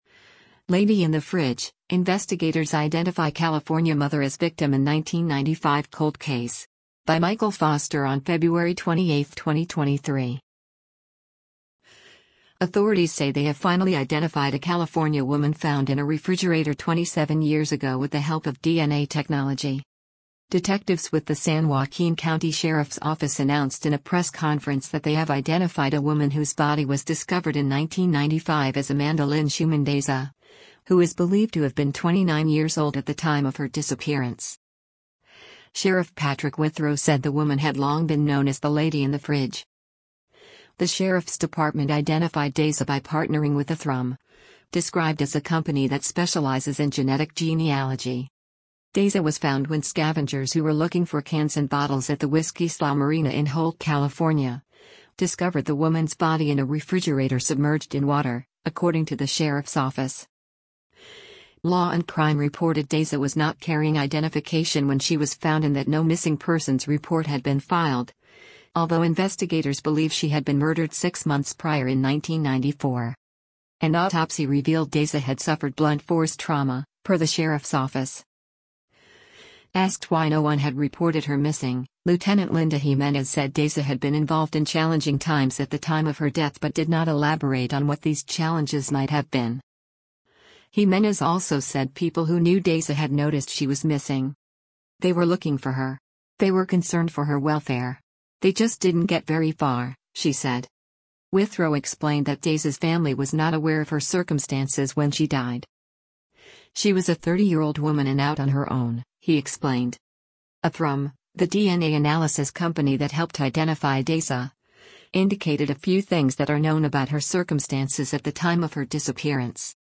Cold Case Update – Press Conference